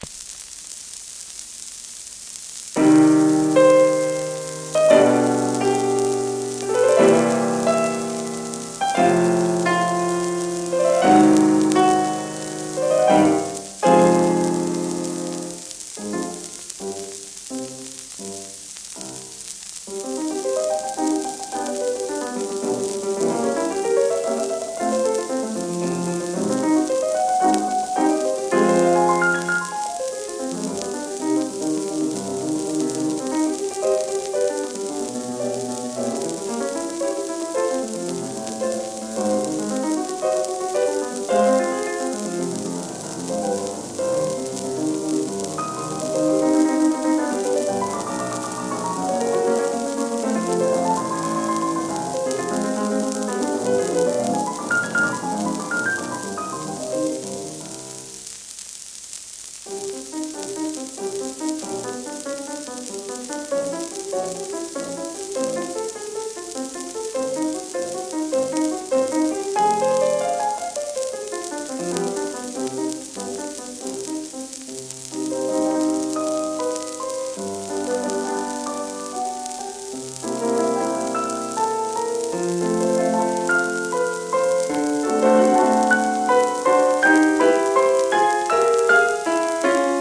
こちらは1951年頃の録音盤。クラシックのSP盤としては最後期の頃になります。